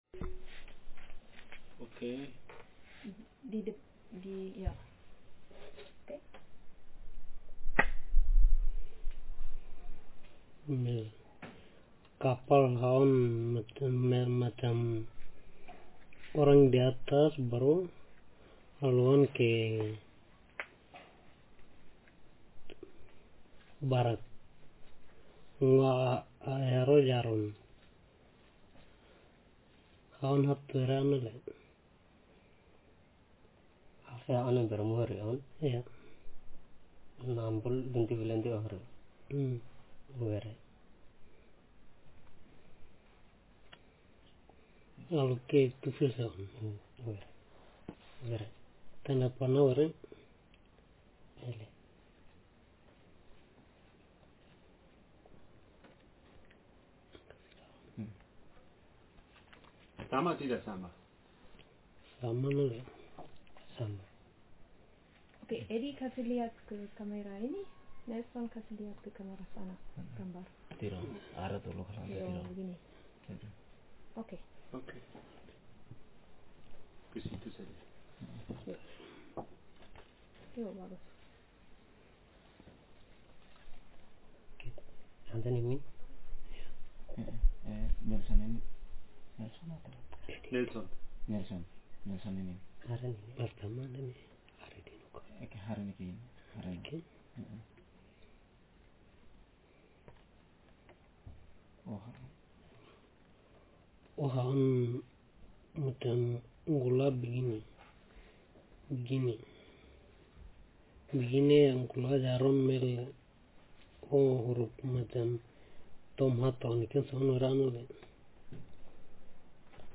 Speaker sexm/m
Text genreconversation